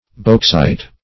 beauxite - definition of beauxite - synonyms, pronunciation, spelling from Free Dictionary
Beauxite \Beaux"ite\, n. (Min.)